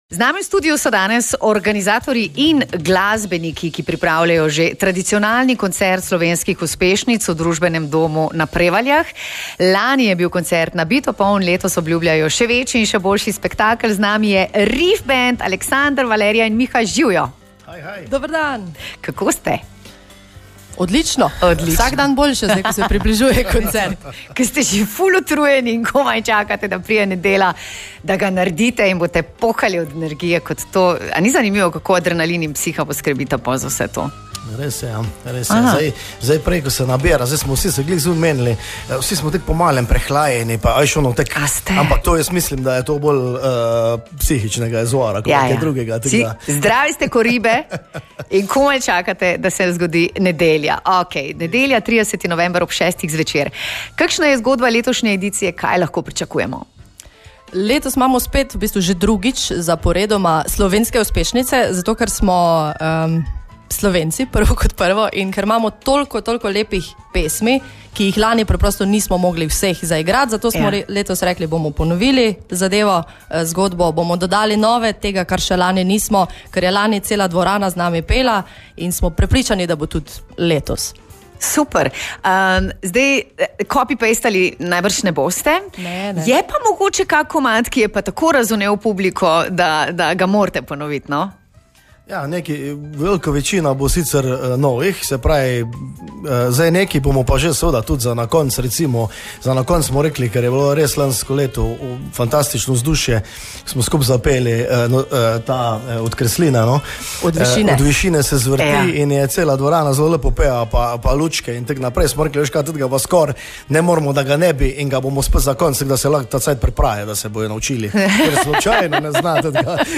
Več o koncertu in s pesmijo v živo tukaj: